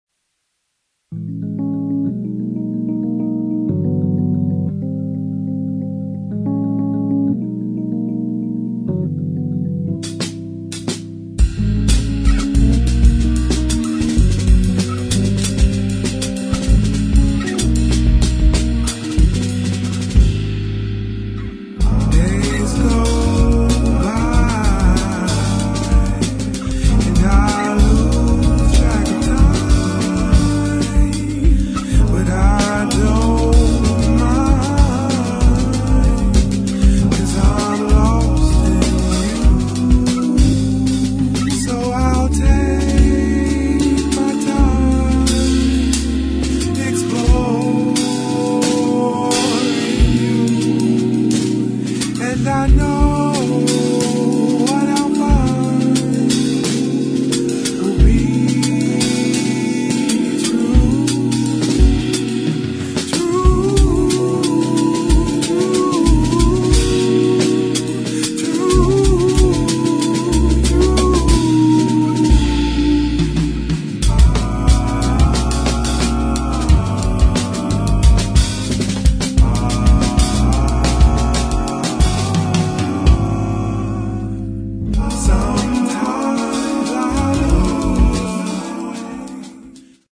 [ HOUSE / AFRO / SOUL ]